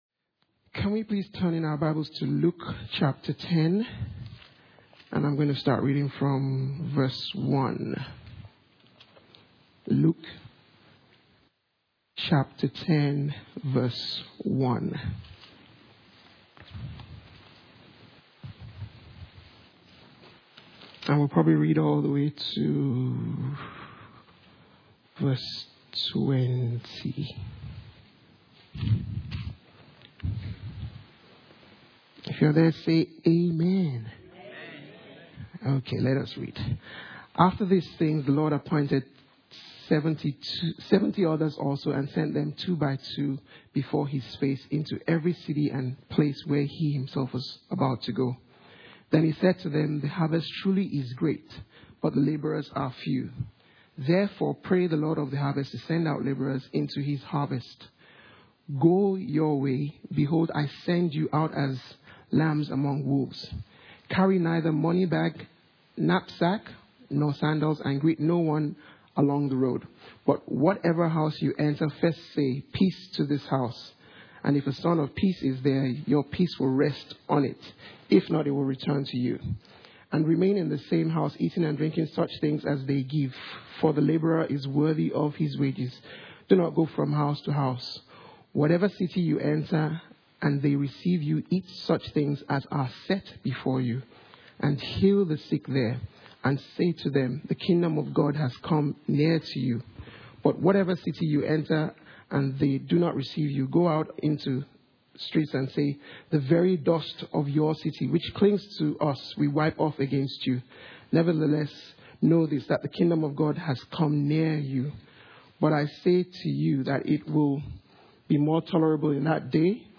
Inhouse Service Type: Sunday Morning « The 7 I AM’s of Jesus P4